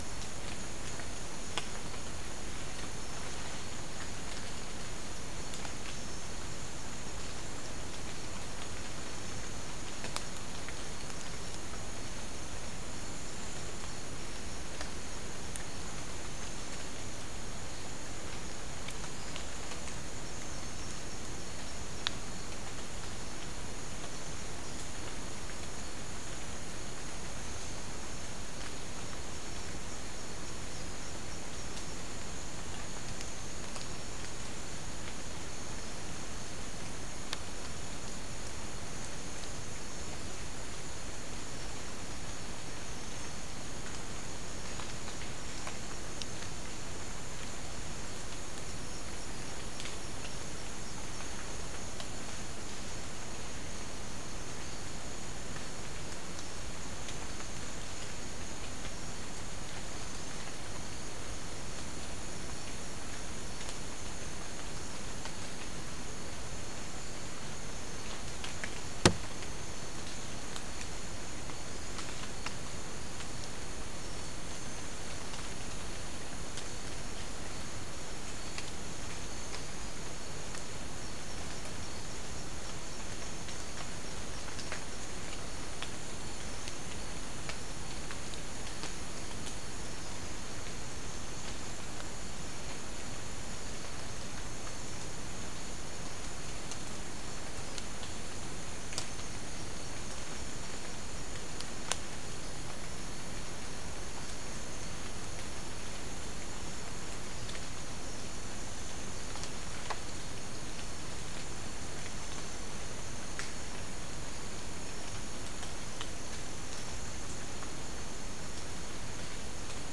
Soundscape
South America: Guyana: Rock Landing: 3
Recorder: SM3